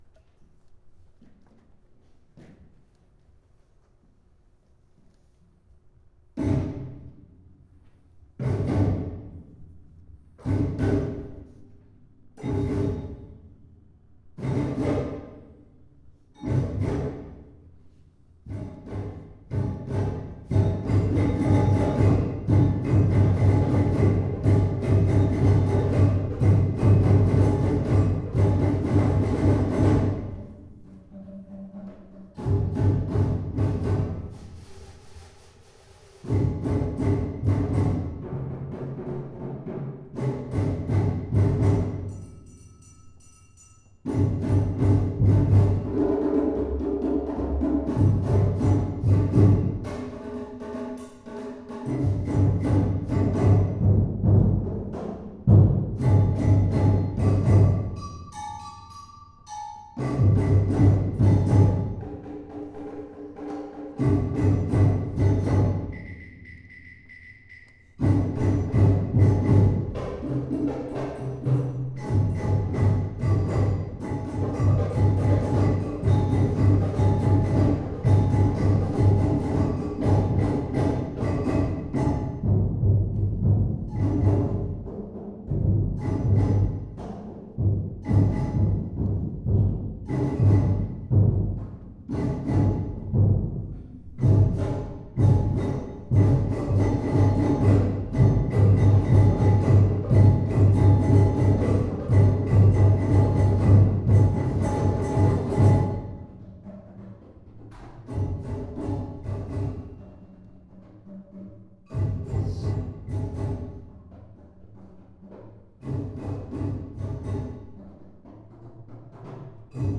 audio - UVM Percussion Ensemble, S19
There are several open sections for solos that can either be performed by one person doing a longer solo, or by many performers doing short solos (you will hear both of those in this performance).